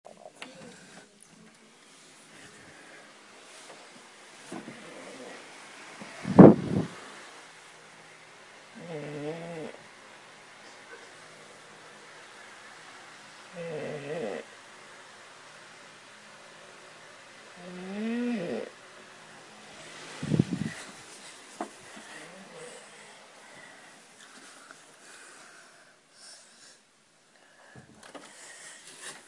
有人打鼾
描述：打鼾的声音，有一些不同的音高变化和不同的打鼾声
Tag: 打鼾 OWI 人打鼾 睡眠 睡觉 打呼噜